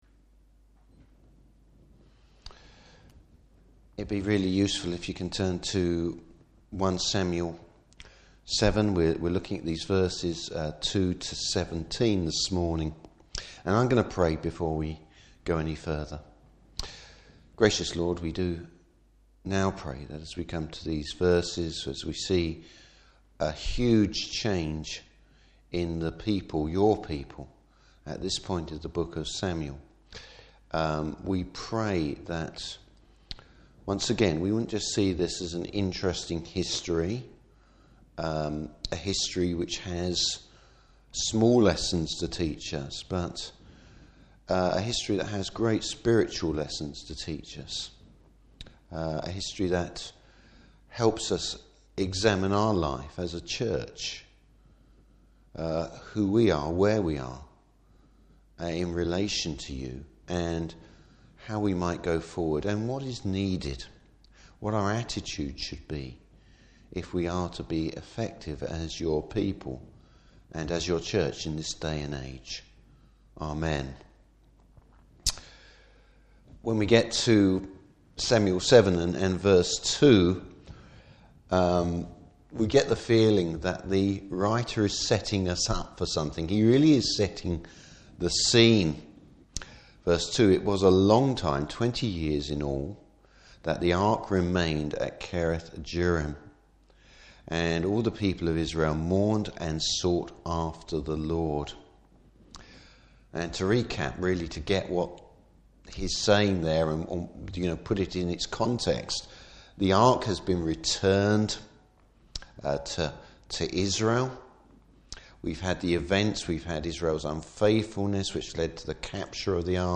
Service Type: Evening Service Revival starts with the Lord’s people!